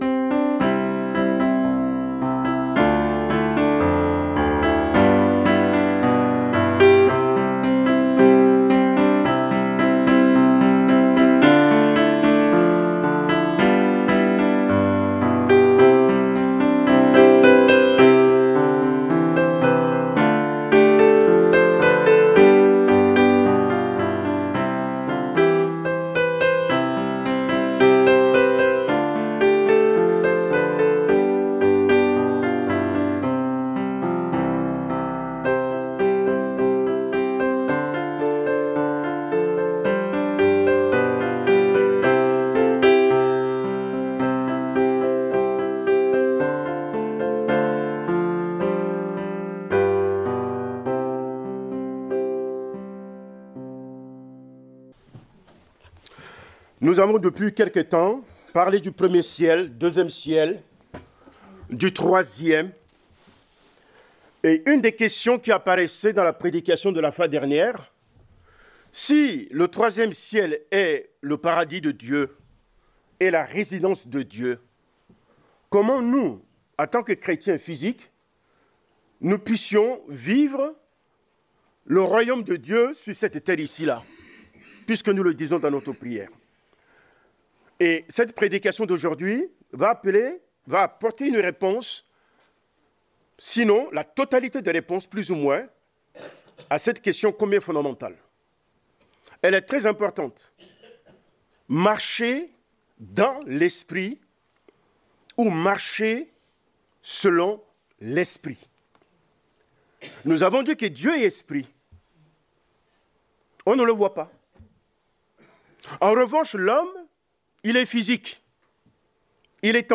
Prédication du 23 février 2025.